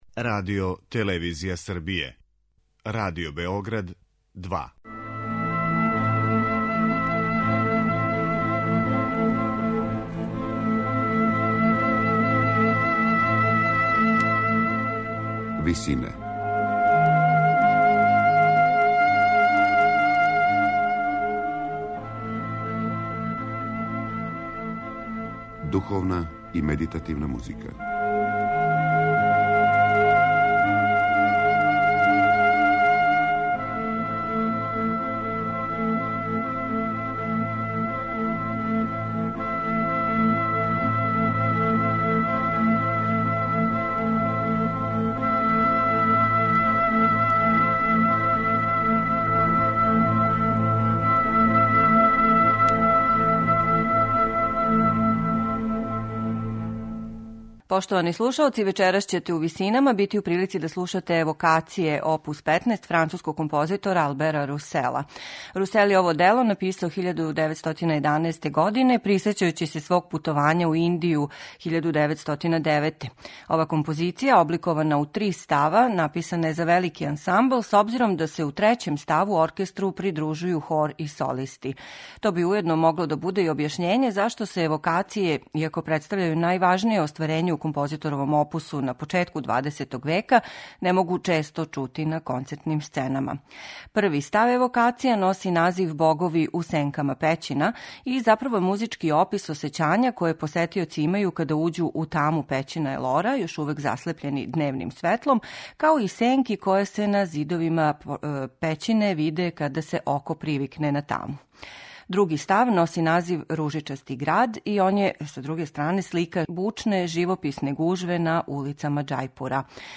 Написано је за солисте, хор и оркестар